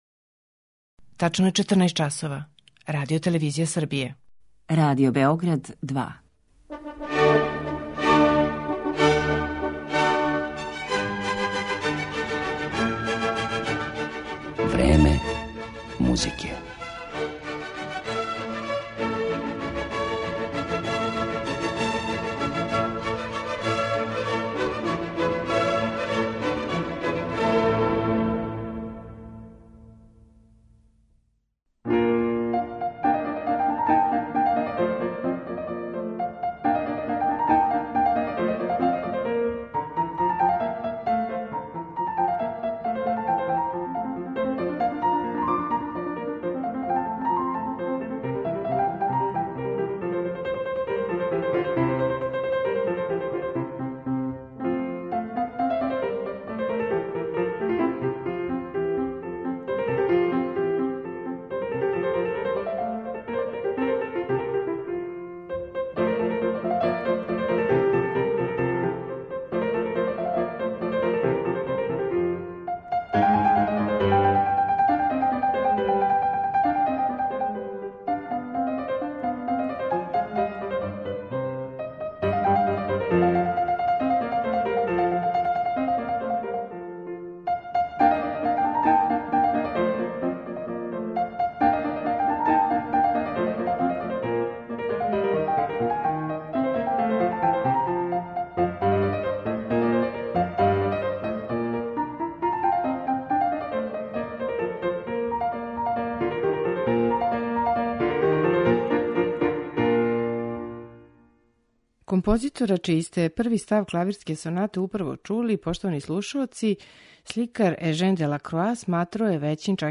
слушаћемо његове клавирске сонате у интерпретацији италијанског пијанисте